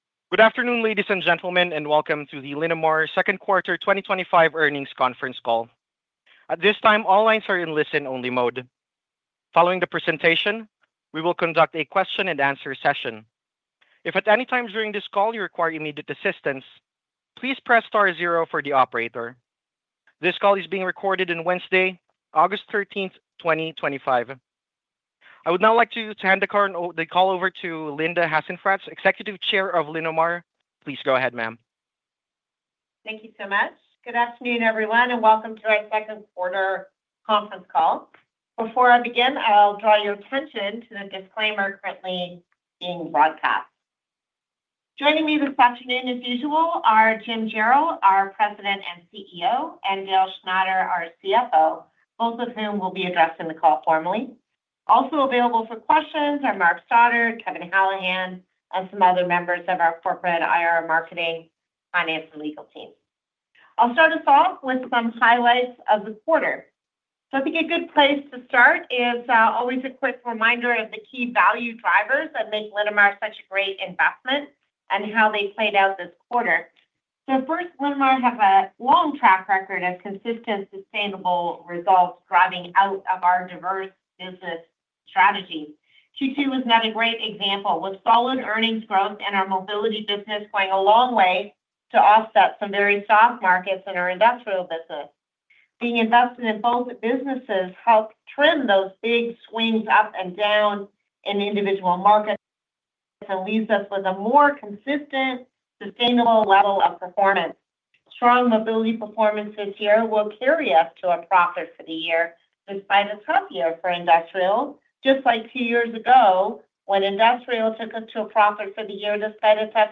Linamar-Q2-2025-Earnings-Call.mp3